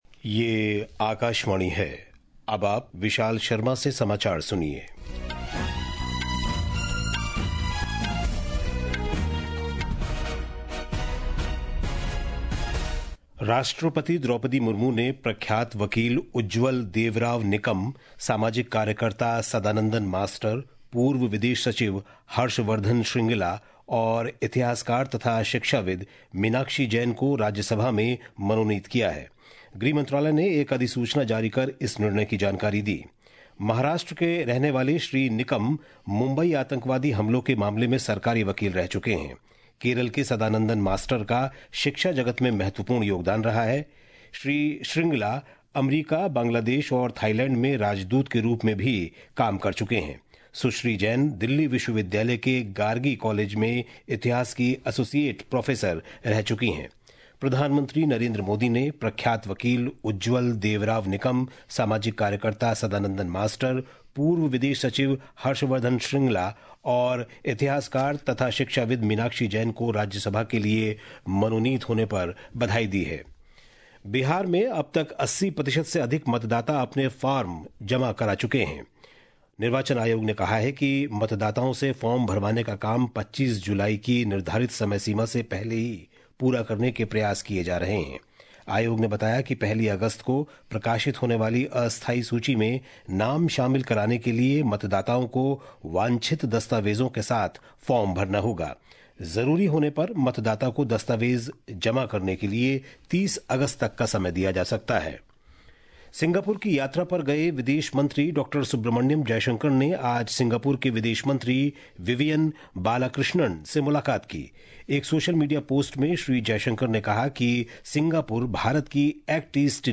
জাতীয় বুলেটিন
प्रति घंटा समाचार